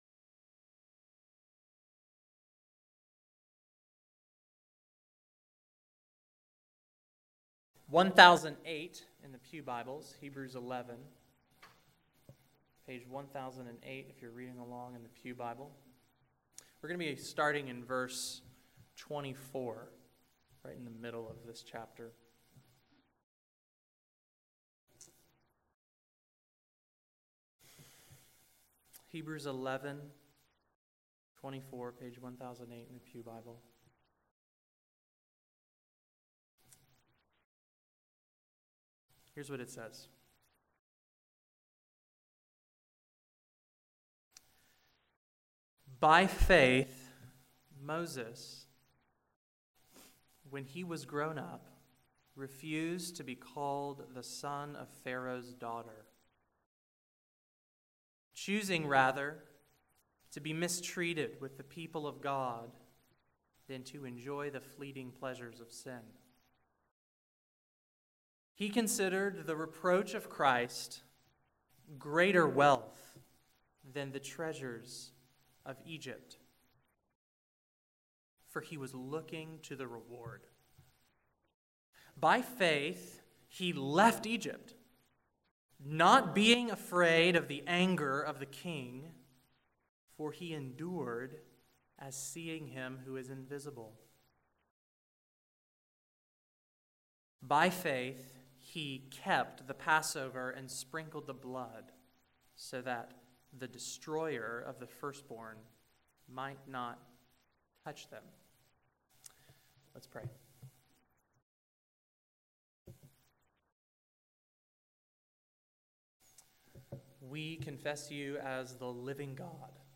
February 26, 2017 Morning Worship | Vine Street Baptist Church